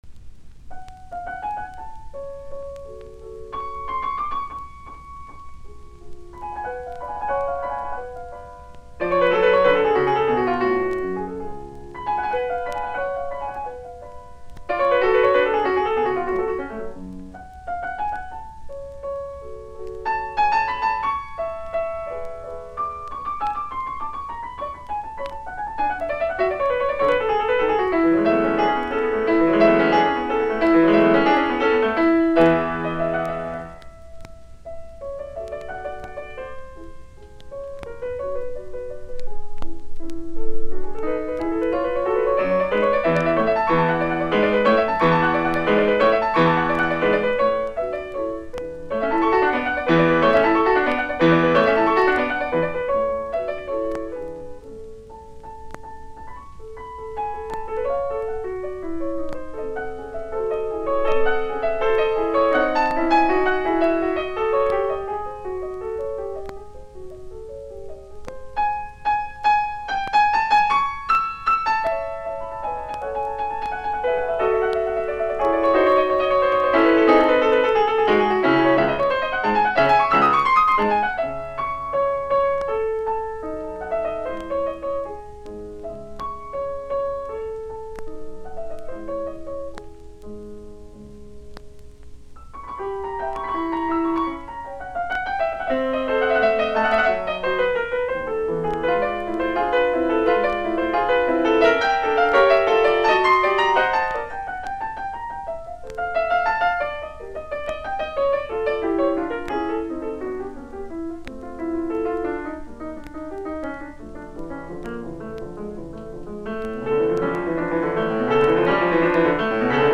Piuttòso allegro con espressione
Horowitz, Vladimir ( piano ) Horowitz plays Clementi sonatas.
Soitinnus: Piano.